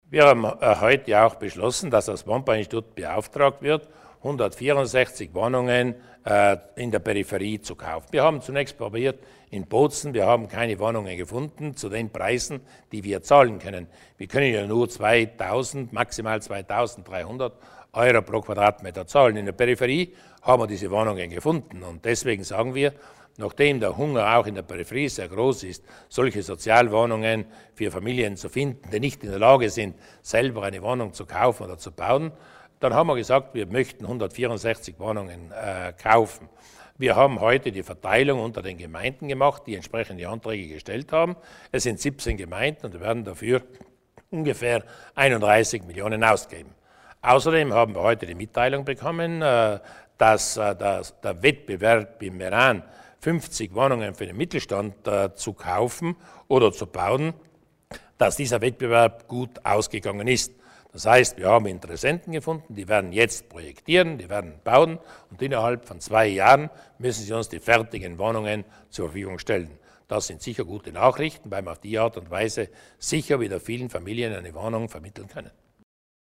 Landeshauptmann Durnwalder erläutert die Projekte des Wohnbauinstituts für die Zukunft